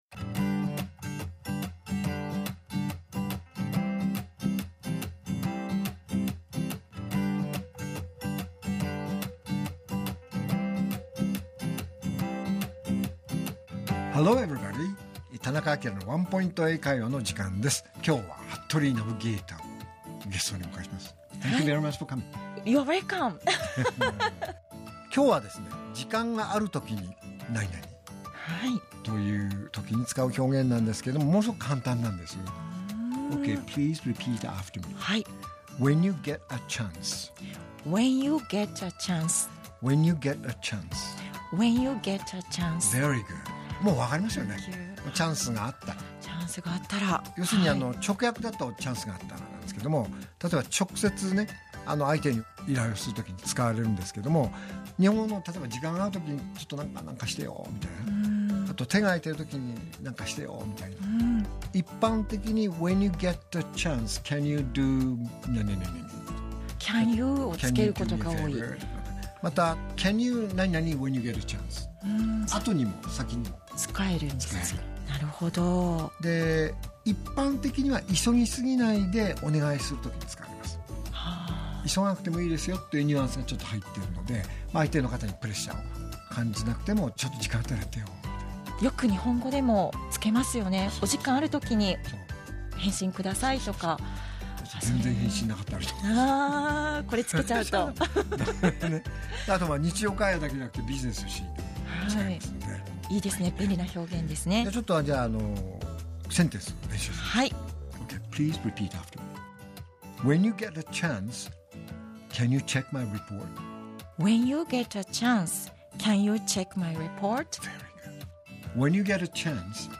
R6.6 AKILA市長のワンポイント英会話